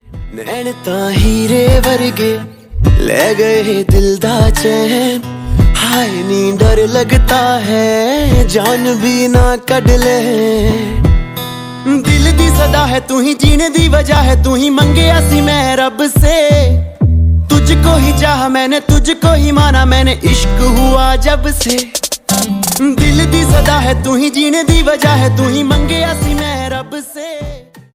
Ringtone Romantic